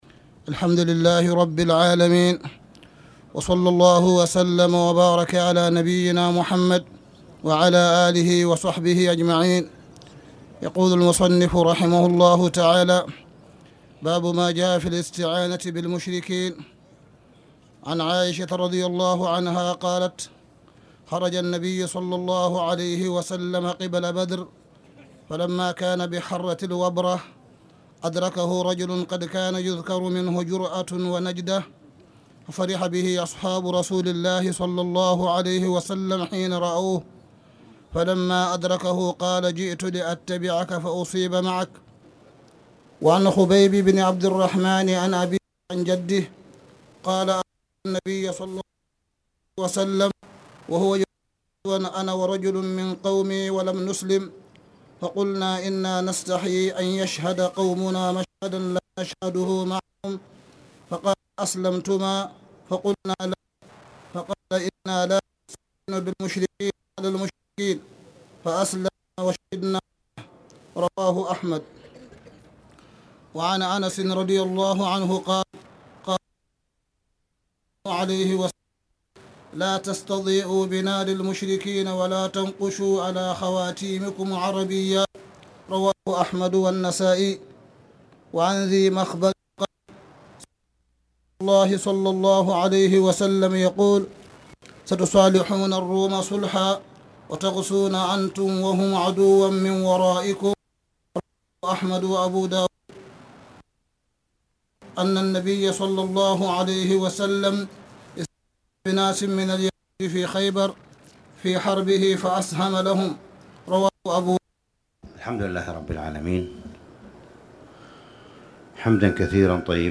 نيل الأوطار أوله متقطع
تاريخ النشر ٢٤ شعبان ١٤٣٧ هـ المكان: المسجد الحرام الشيخ: معالي الشيخ أ.د. صالح بن عبدالله بن حميد معالي الشيخ أ.د. صالح بن عبدالله بن حميد نيل الأوطار أوله متقطع